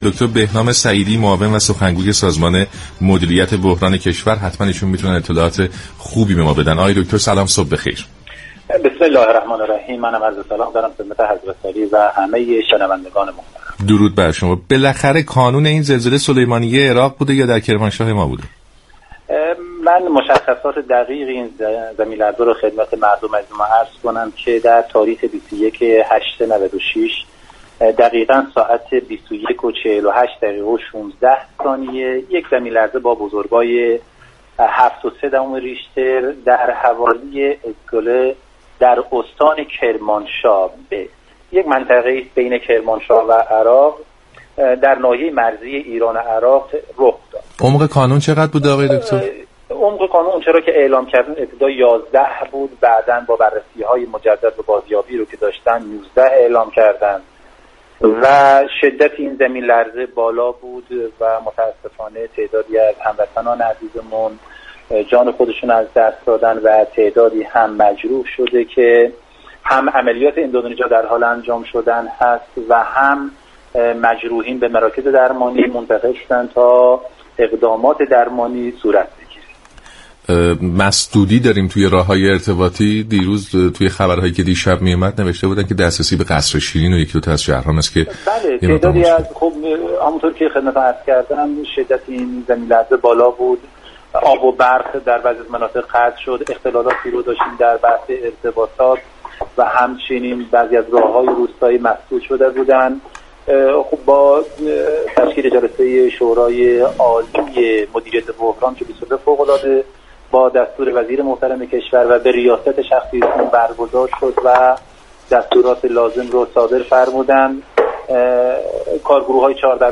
بهنام سعیدی معاون و سخنگوی سازمان مدیرت بحران كشور در گفت و گو با سلام ایران گفت